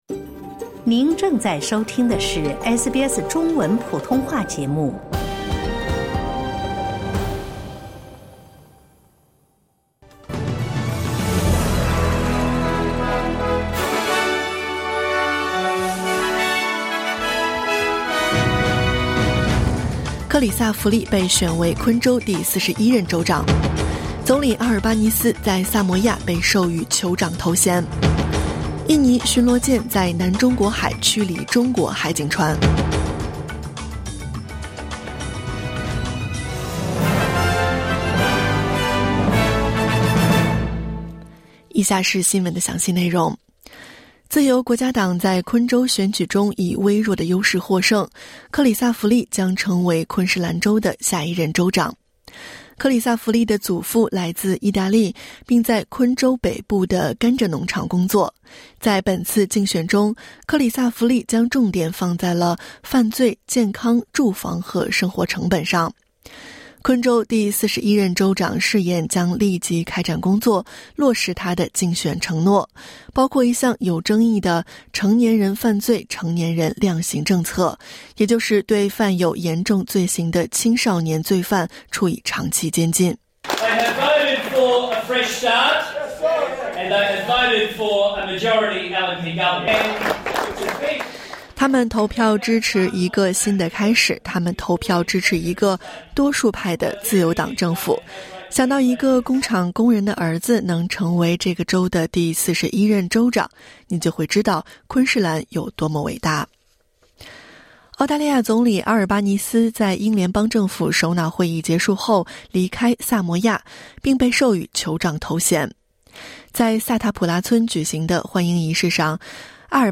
SBS早新闻（2024年10月27日）
SBS Mandarin morning news Source: Getty / Getty Images